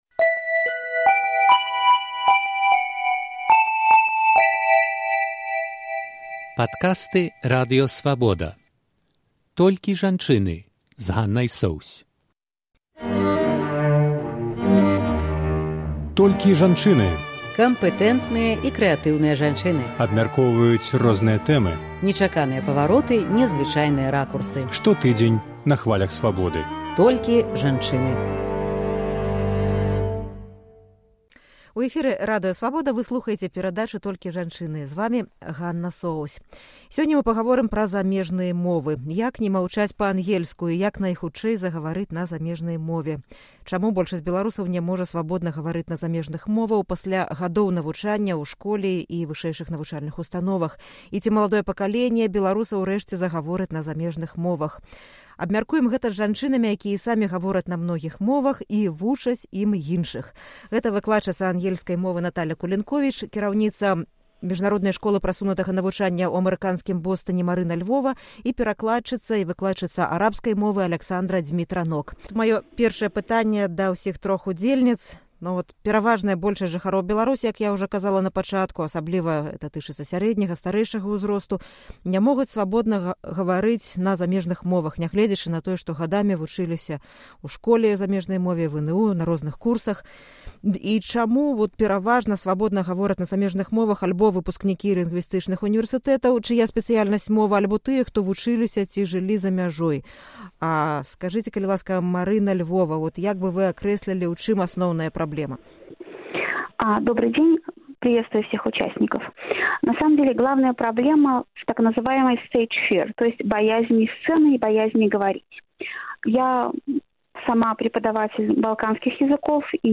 Як найхутчэй загаварыць на замежнай мове? Абмяркоўваюць выкладчыца ангельскай мовы